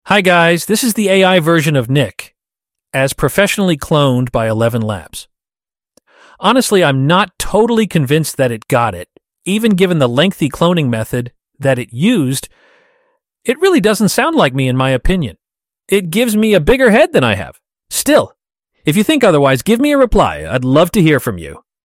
Acording to Eleven Labs' professionals...I sound like this.
Kinda very pretentious professionalism.
But also I prefer real you haha. that clone just sounds cold.
And it doesn't have my accent.